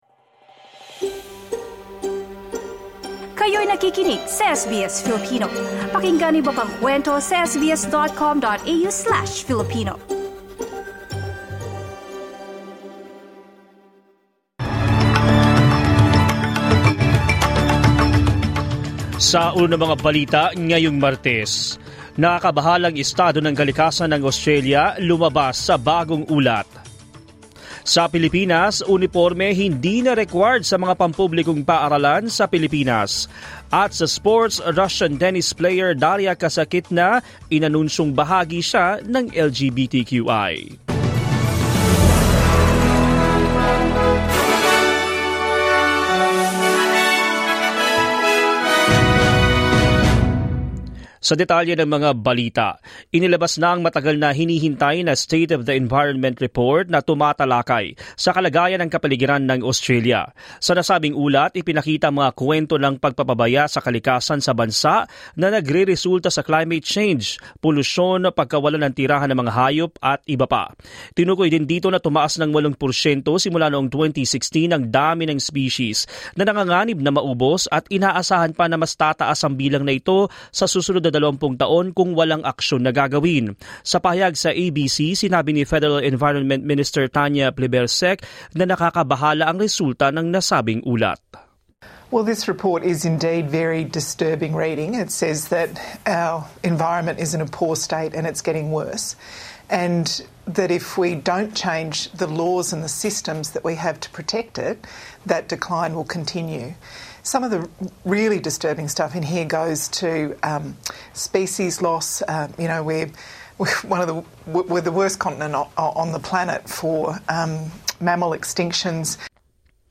SBS News in Filipino, Tuesday 19 July